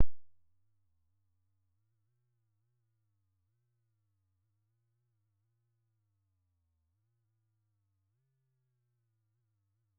然后记录了未连接到输入引脚的10秒采样：
很好很安静 我没有听到任何噪音。